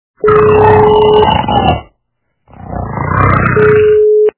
Мужчина - Храп Звук Звуки Чоловік - хропіння
При прослушивании Мужчина - Храп качество понижено и присутствуют гудки.